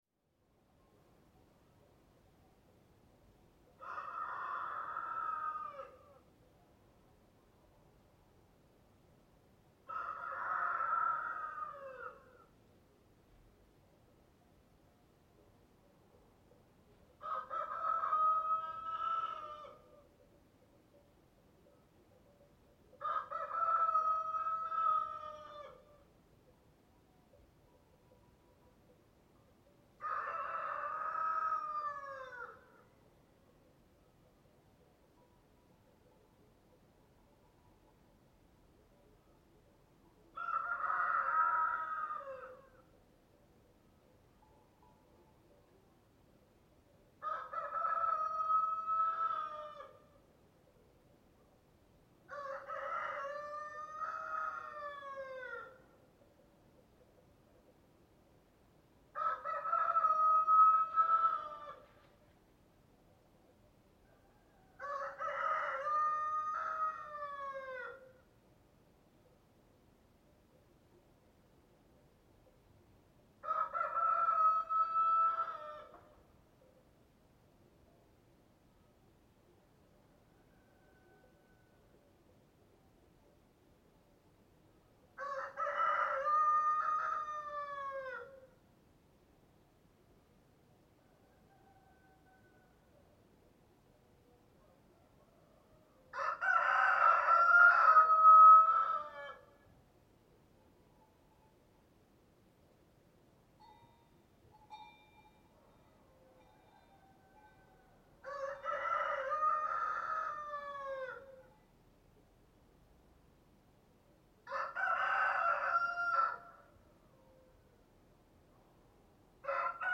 دانلود صدای خروس در روستا و در هنگام سکوت از ساعد نیوز با لینک مستقیم و کیفیت بالا
جلوه های صوتی